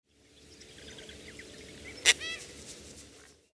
Nyctanassa violacea
Nome em Inglês: Yellow-crowned Night-Heron
Aprecie o canto do
Savacu-de-coroa